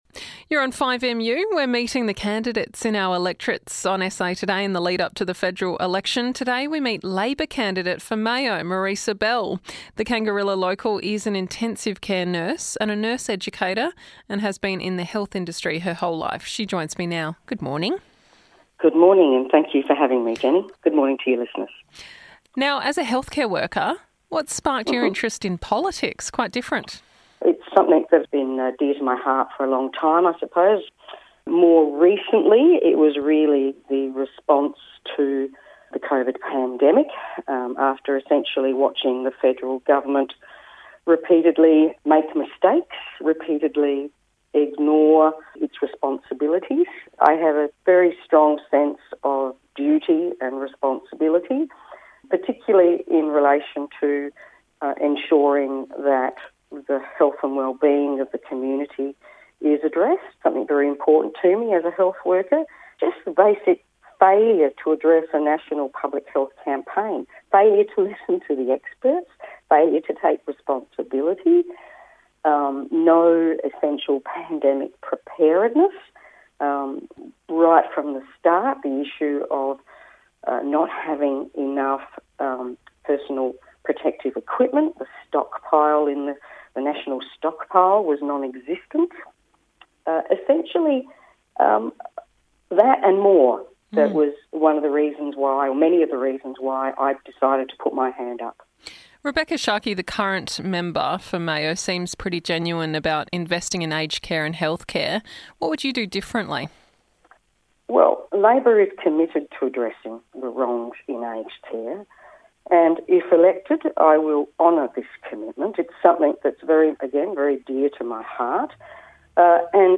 As part of a series of discussions with Mayo and Barker candidates